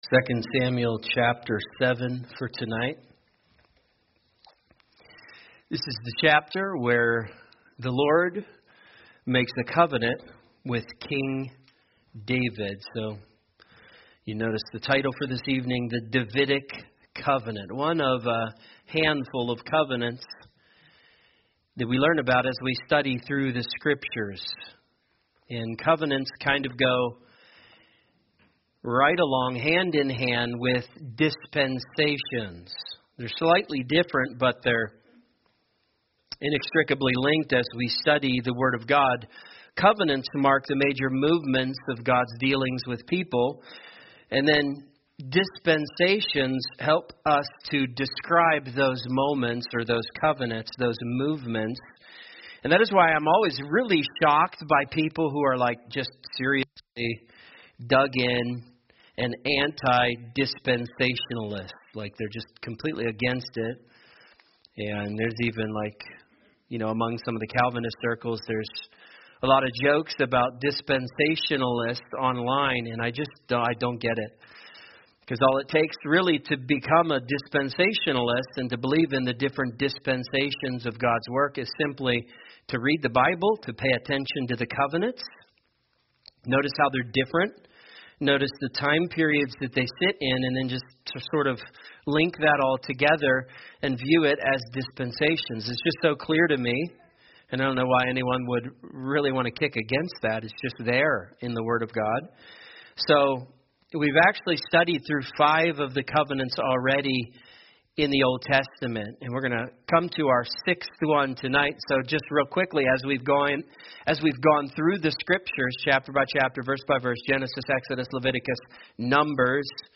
A message from the topics "The Book of 2 Samuel."